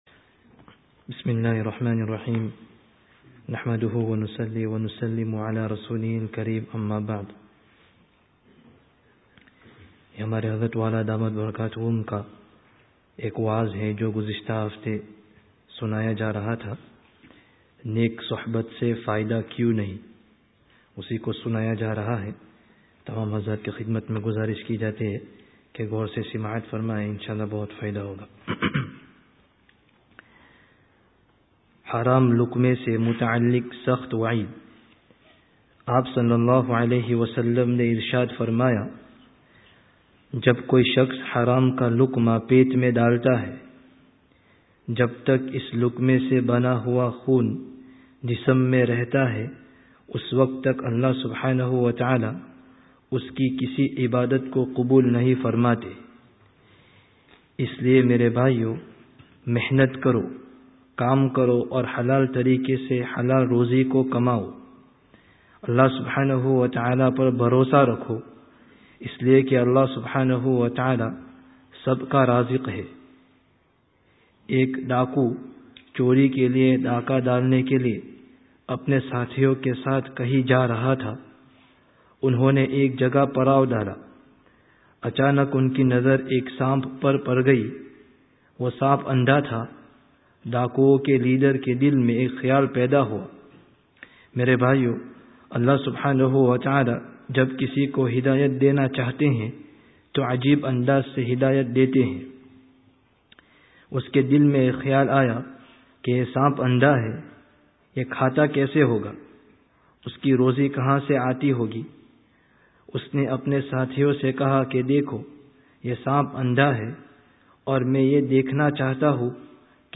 Download 3rd saturday 2013 wa'z bil-kitab friday tazkiyah gathering Related articles Wa'z Bil-Kitāb: Har Jagah Kām Āney Wālī Chīz (22/02/13) Wa'z Bil-Kitāb: Neyk Suhbat se Fā'idah kyu(n) nahi(n)?